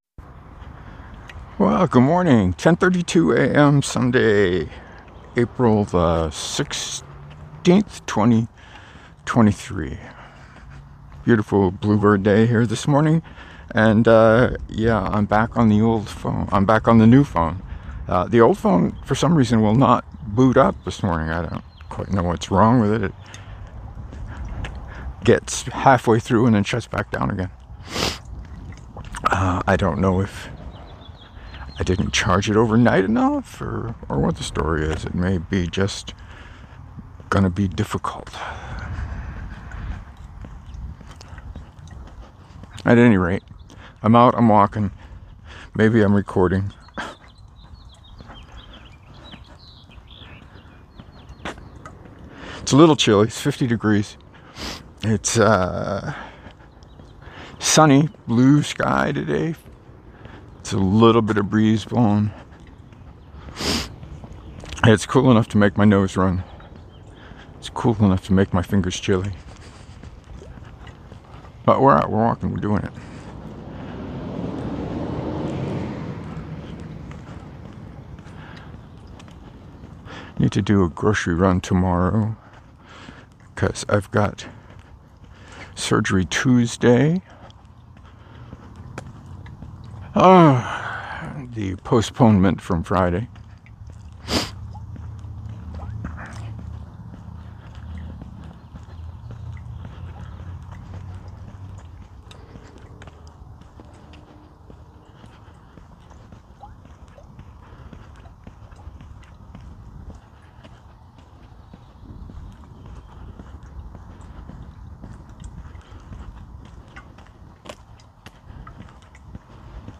A bit chilly but a lovely morning for a ramble.
I recorded on the Note S20.